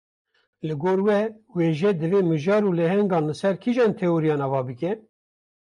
Pronounced as (IPA)
/weːˈʒɛ/